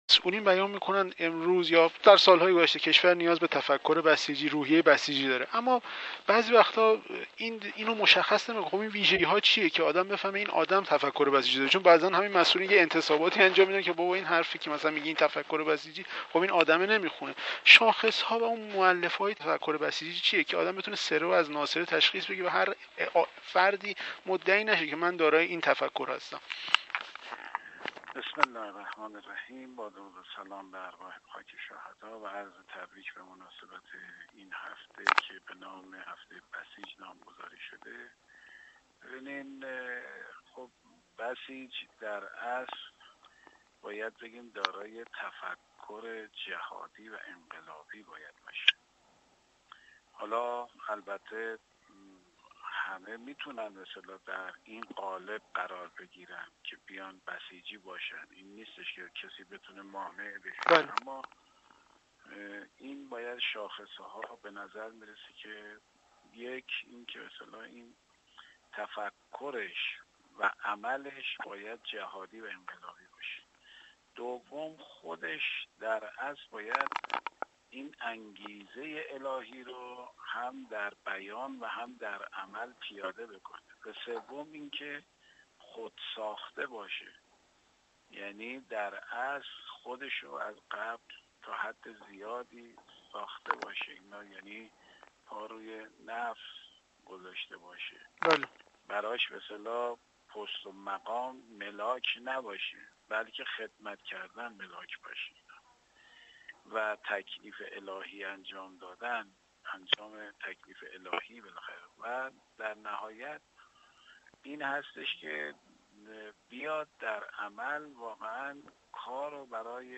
سردار کوثری پاسخ می‌دهد:
سردار اسماعیل کوثری، عضو کمیسیون فرهنگی مجلس شورای اسلامی، در گفت‌وگو با ایکنا، درباره مشخصات و مؤلفه‌های تفکر و روحیه بسیجی گفت: نیروی بسیجی نیرویی با تفکر جهادی و انقلابی است و همه می‌توانند در قالب بسیج فعالیت کنند و کسی نمی‌تواند مانع شود اما مشخصه یک بسیجی واقعی این است که از تفکر و عمل جهادی و انقلابی برخوردار باشد، در بیان و عمل انگیزه‌های الهی داشته و فردی خودساخته باشد.